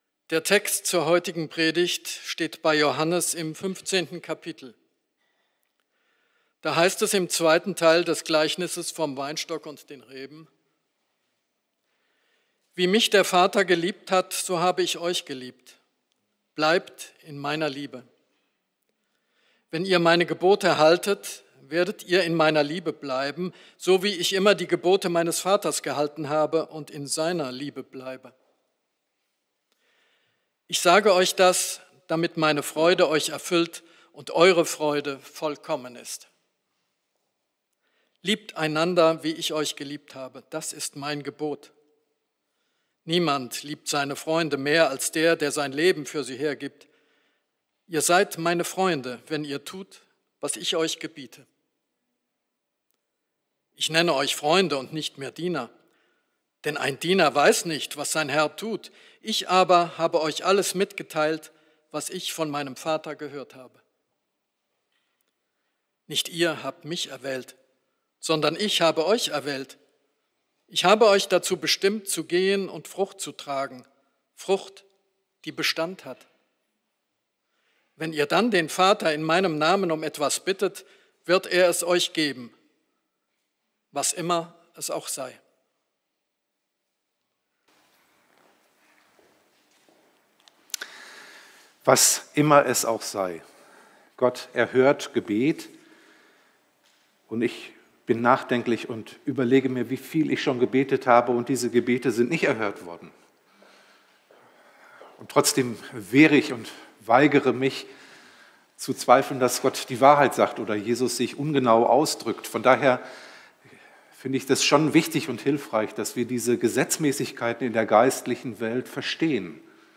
02-Predigt-1.mp3